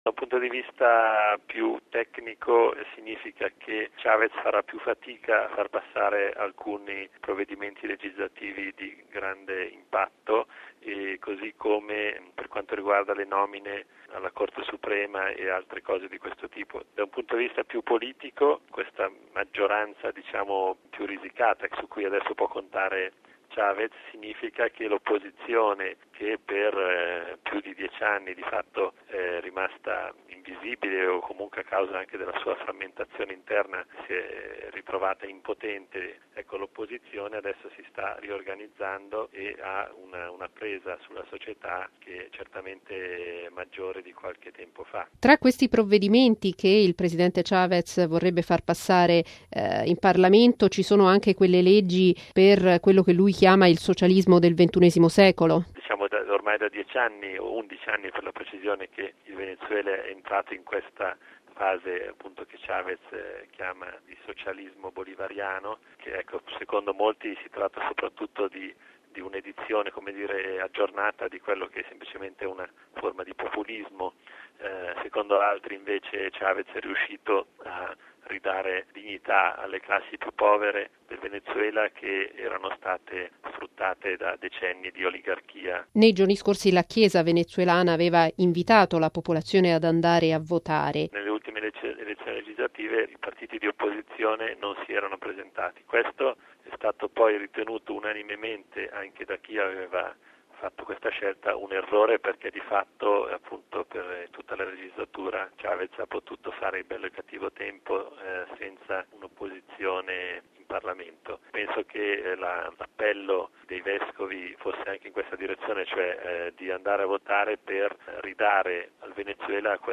Per un commento sul risultato di queste parlamentari